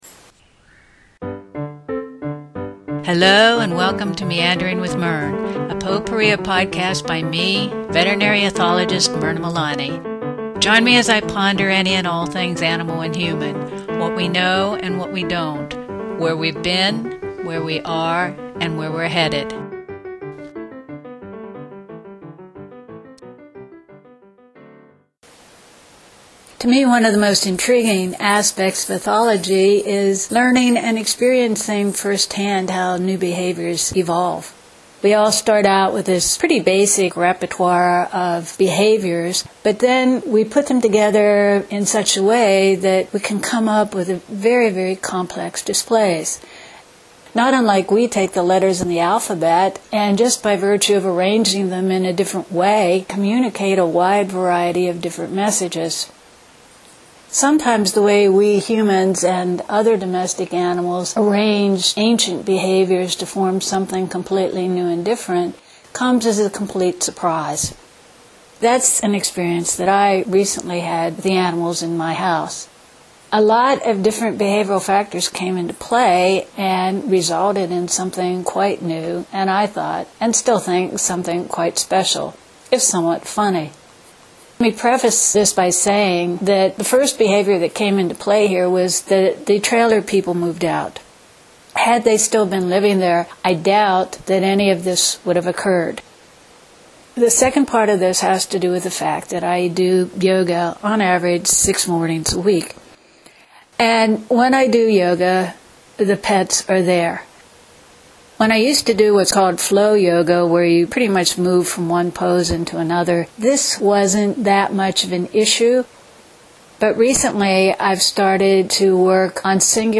If you have dogs, you may want to listen to this when they’re not around or else keep the volume down.